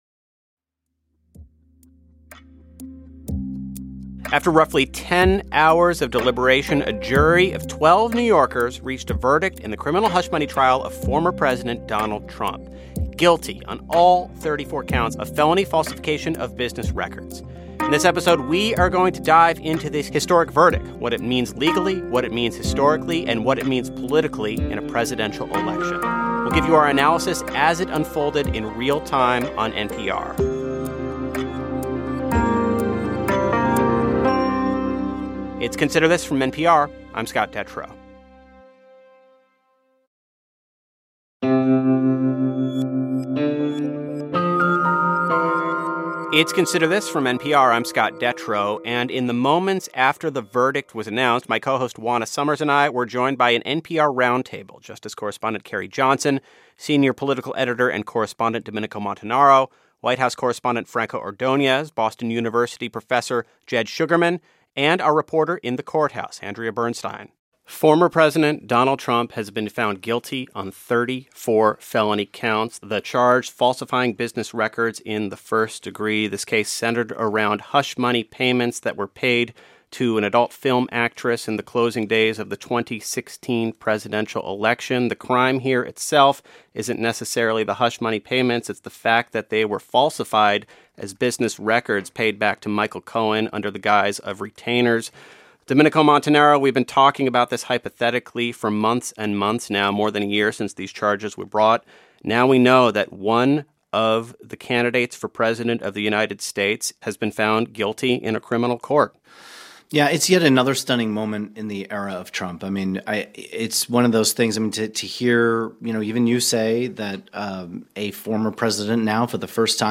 Analysis